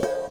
Drum Samples
C r a s h e s